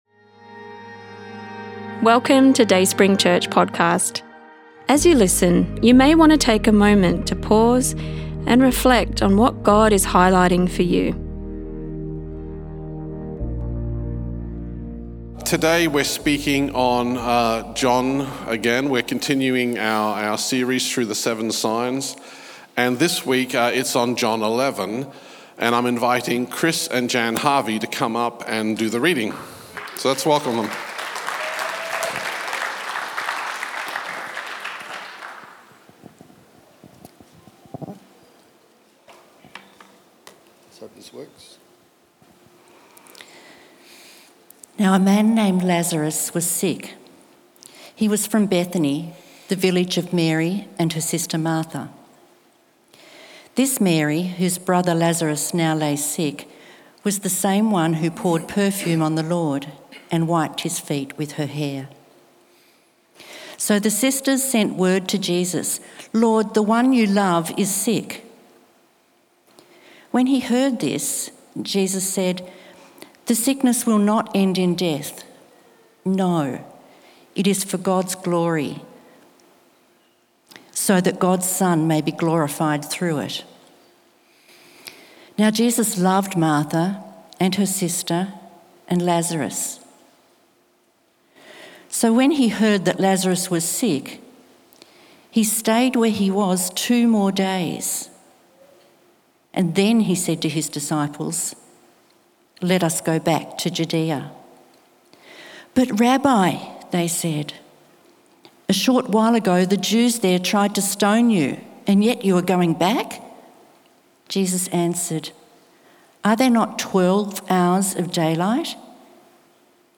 In this insightful sermon, we journey through John 11 and the profound story of Lazarus. Unpacking Jesus's emotional response and the raising of Lazarus, we explore the significance of living authentically before God and others.